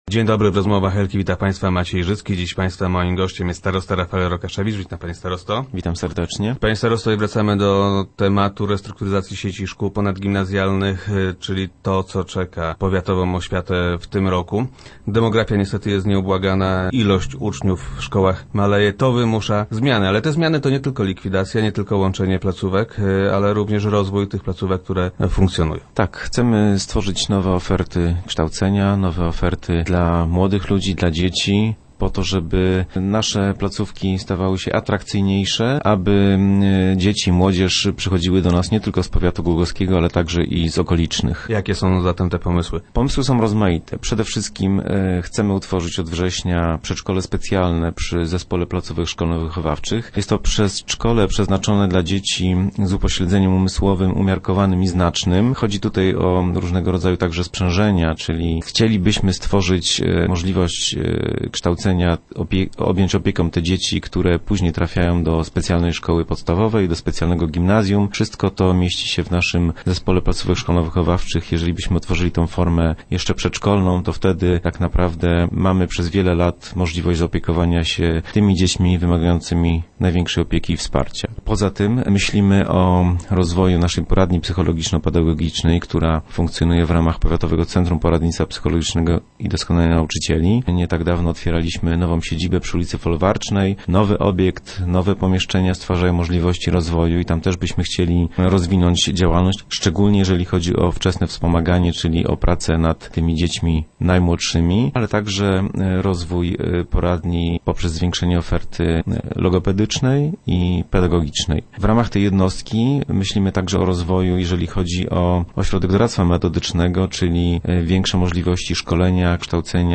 - Chcemy rozwijać też placówki, które już u nas funkcjonują – twierdzi starosta Rafael Rokaszewicz, który był gościem Rozmów Elki.